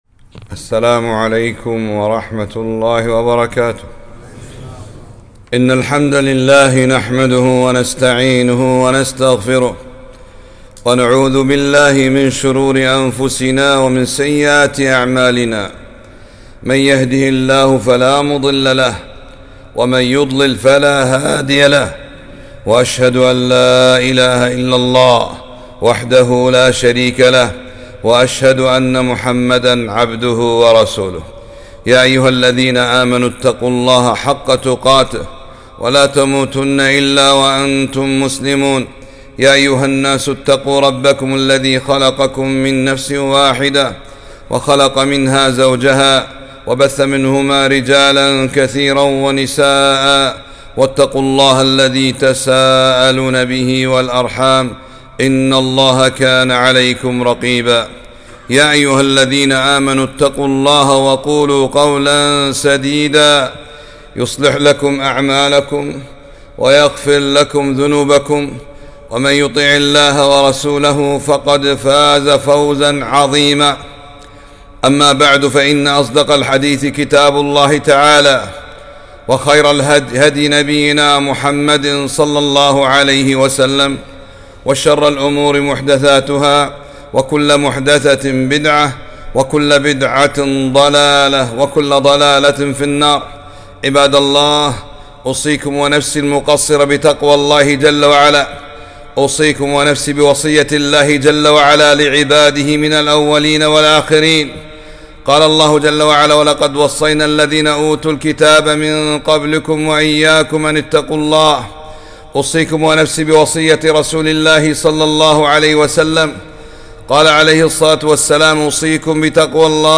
خطبة - هل تعلم كم آية في القرآن في بر الوالدين ؟